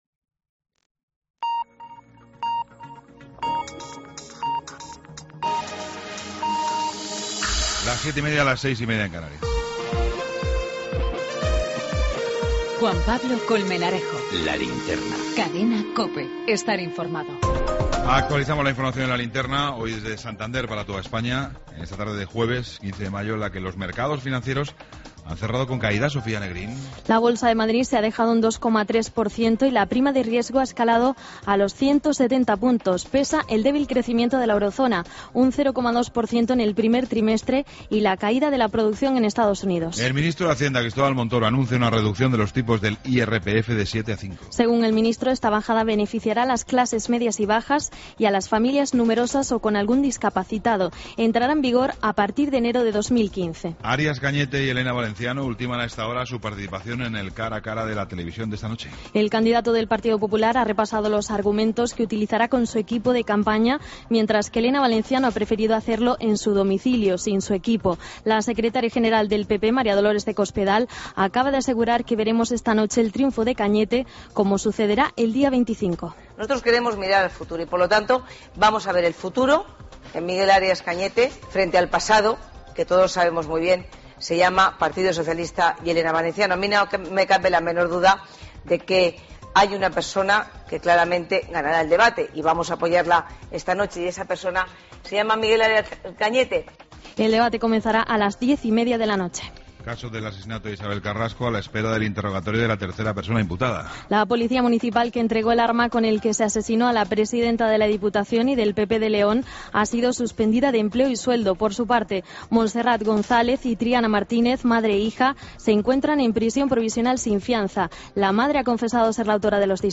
Ronda de corrresponsales.